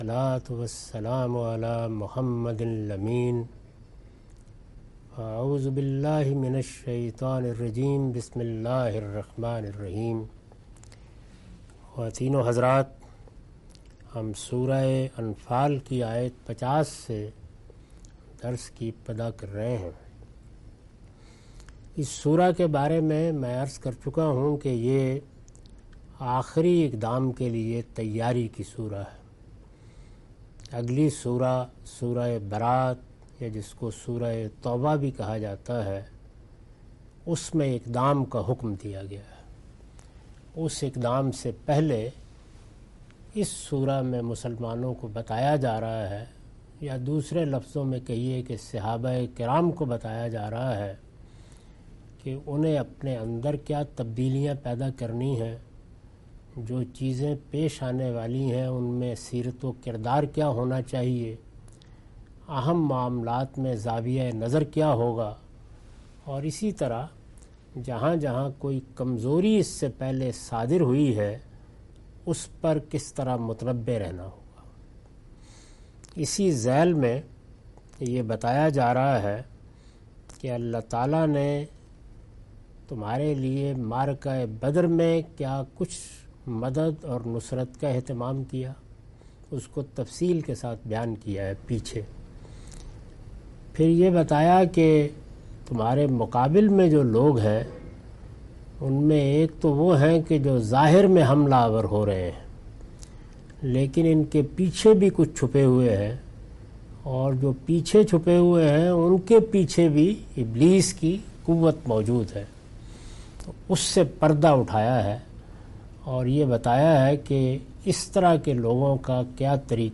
Surah Al-Anfal - A lecture of Tafseer-ul-Quran – Al-Bayan by Javed Ahmad Ghamidi. Commentary and explanation of verses 50-53.